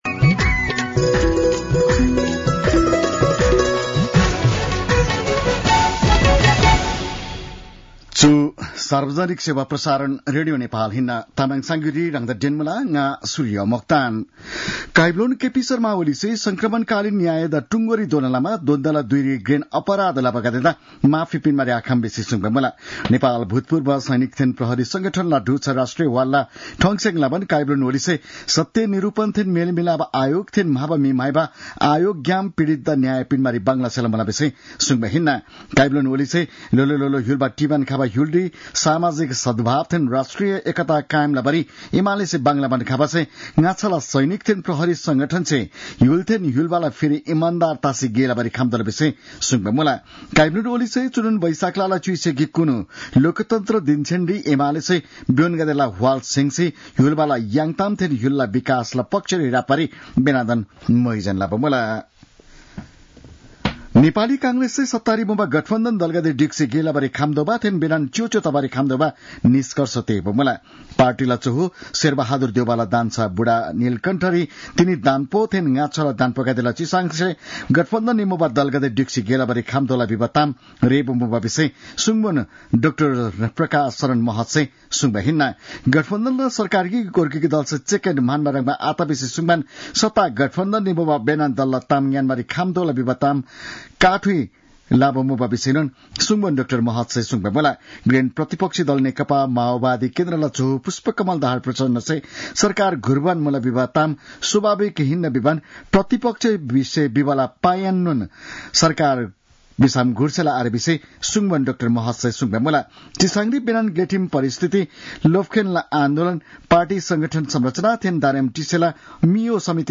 तामाङ भाषाको समाचार : ४ वैशाख , २०८२